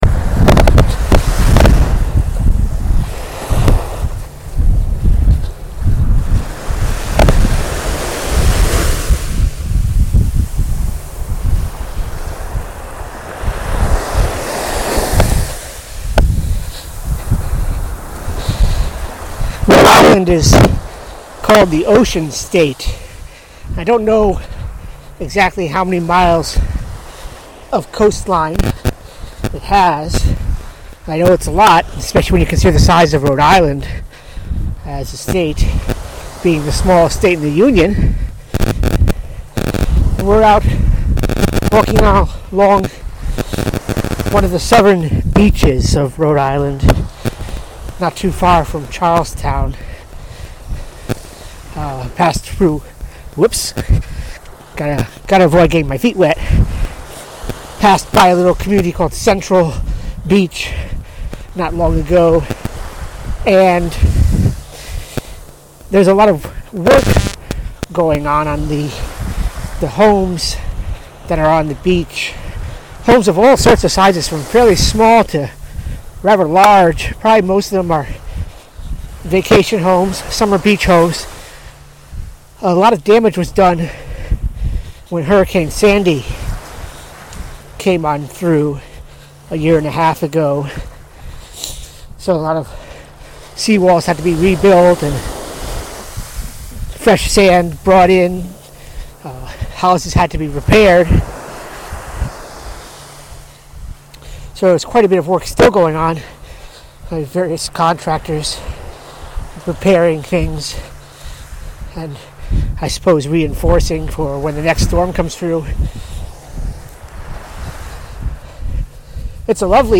Rhode Island beach walk.mp3